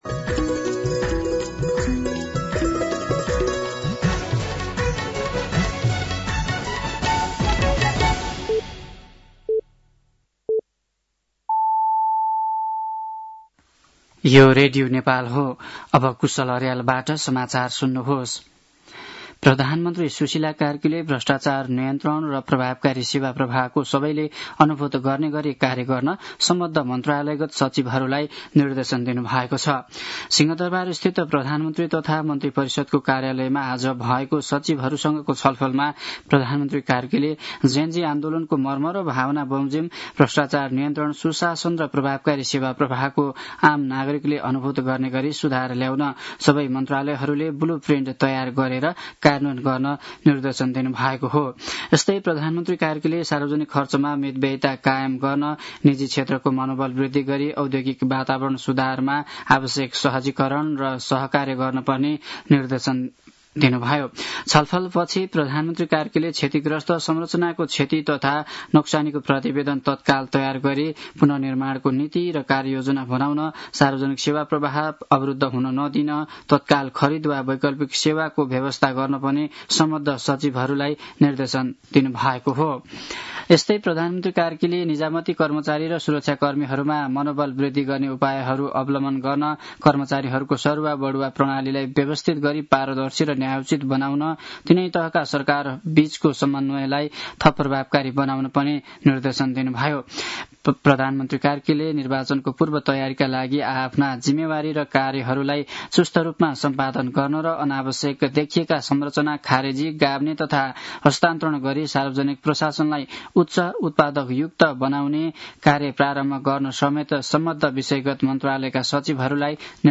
साँझ ५ बजेको नेपाली समाचार : १७ कार्तिक , २०८२
5.-pm-nepali-news-.mp3